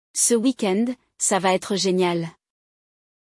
No episódio de hoje, vamos mergulhar em um diálogo animado entre dois amigos que falam sobre o próximo fim de semana.
Você primeiro escuta a conversa em francês e, em seguida, analisamos cada frase juntos, para que você entenda completamente como usar as palavras no seu dia a dia.